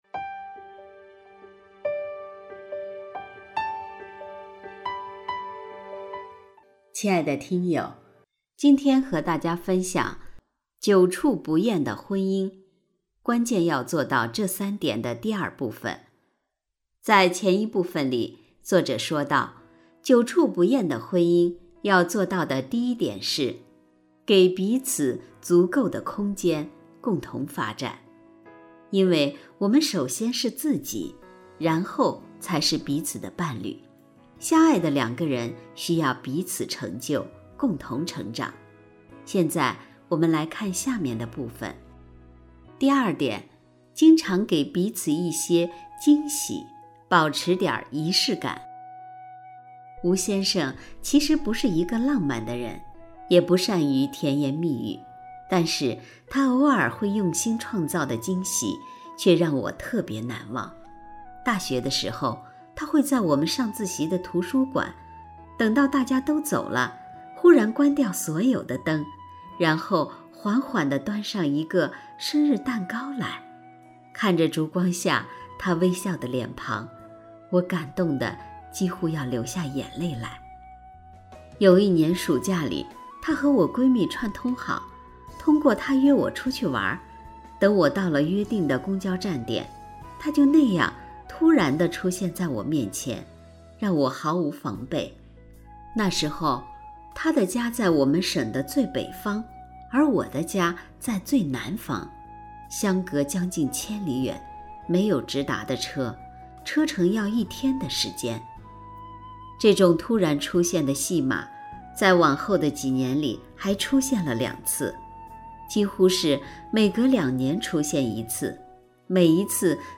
首页 > 有声书 > 婚姻家庭 > 单篇集锦 | 婚姻家庭 | 有声书 > 久处不厌的婚姻，关键要做到这三点（二）